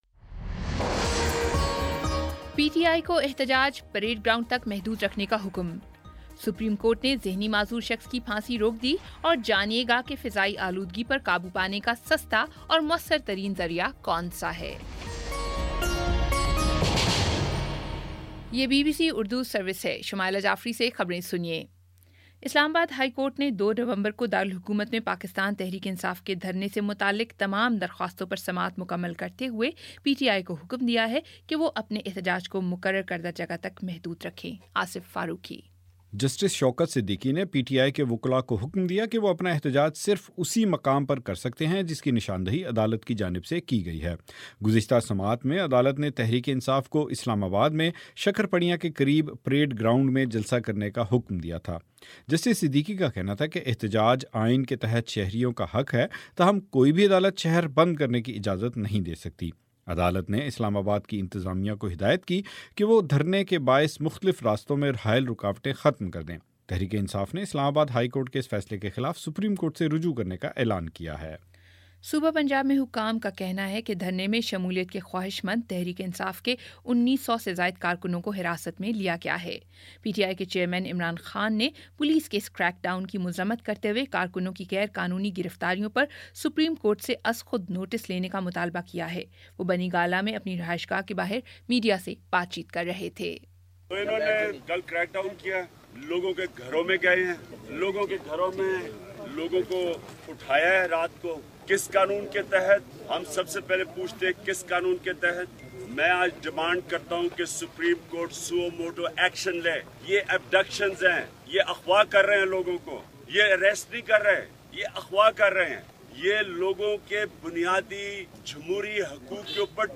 اکتوبر 31 : شام پانچ بجے کا نیوز بُلیٹن